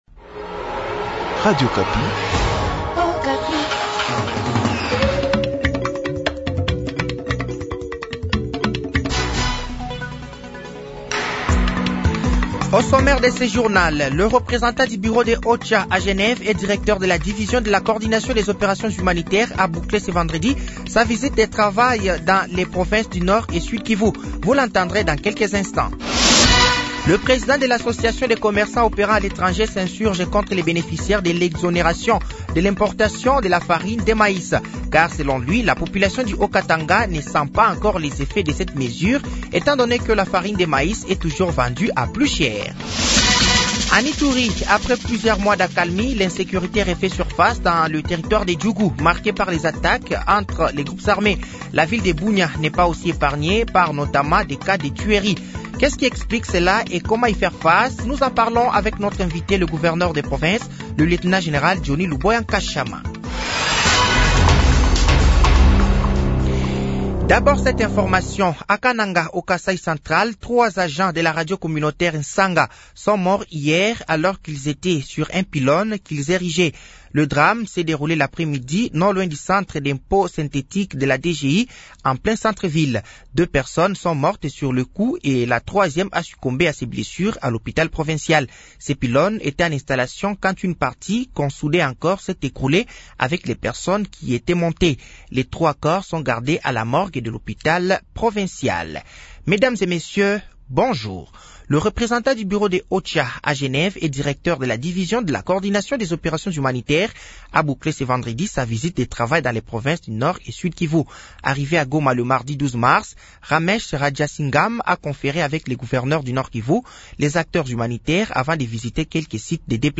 Journal français de 7h de ce samedi 16 mars 2024